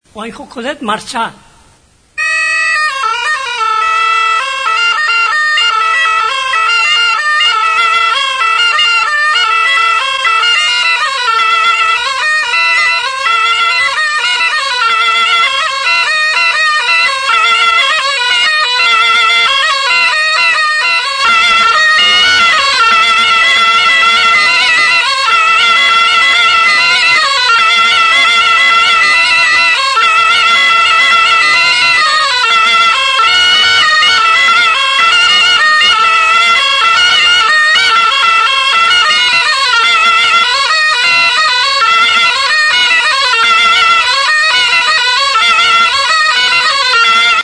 Music instrumentsAlboka
Aerophones -> Reeds -> Single fixed (clarinet)
EUROPE -> EUSKAL HERRIA
ALBOKA
Klarinete bikoitza da.